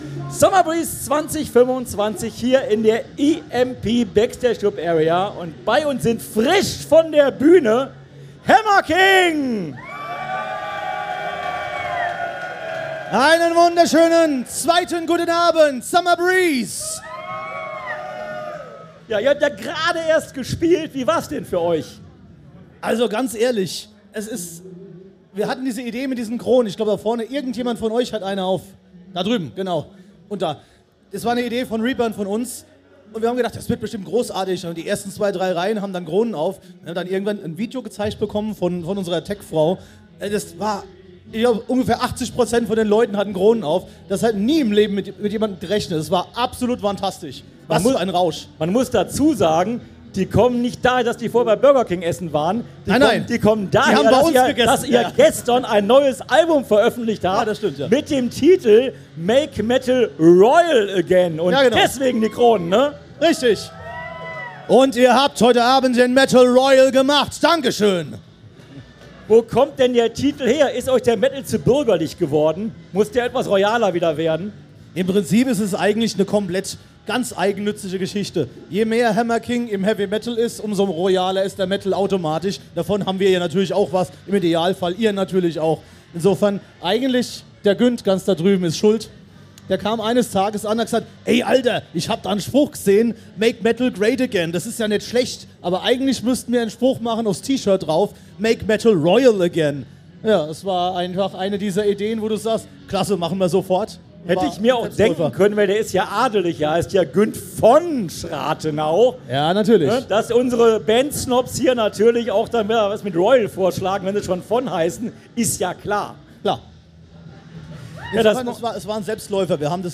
Summer Breeze 2025 Special - Hammer King - Live aus der EMP Backstage Club Area